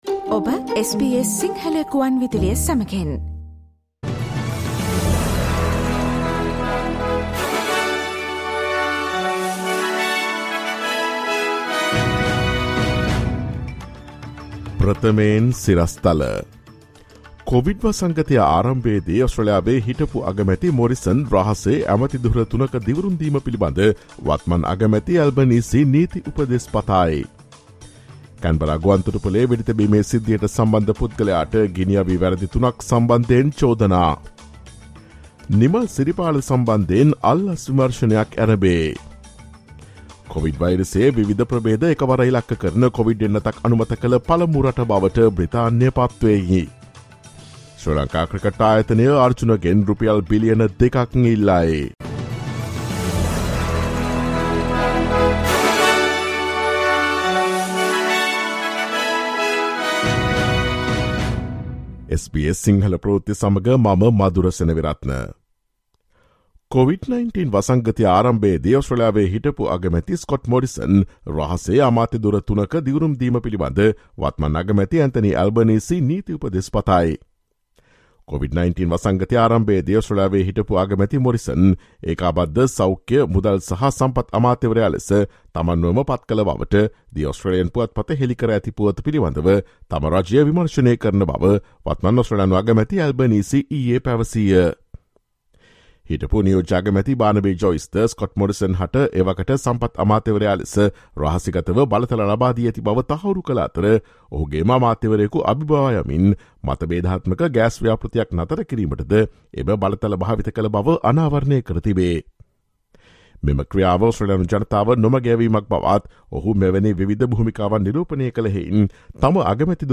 Listen to the latest news from Australia, Sri Lanka, and across the globe, and the latest news from the sports world on SBS Sinhala radio news – Tuesday, 16 August 2022.